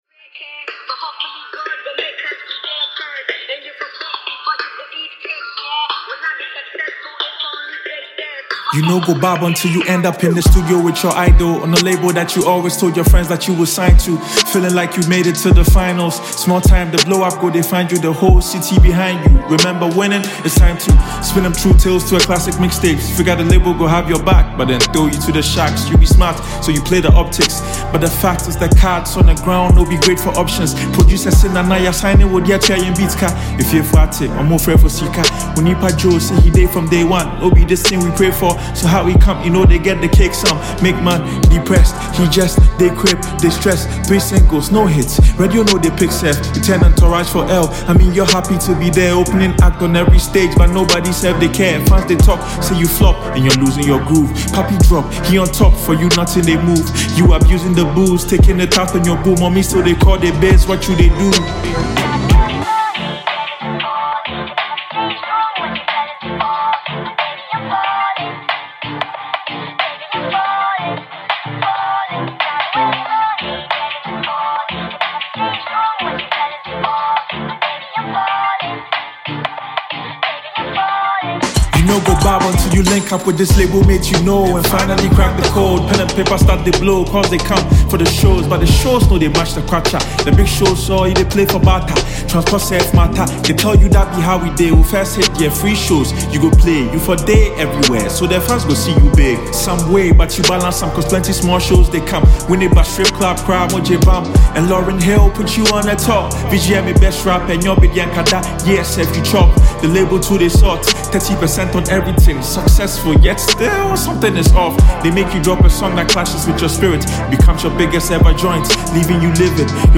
GHANA MUSIC
Lyrically Gifted Ghanaian rapper and songwriter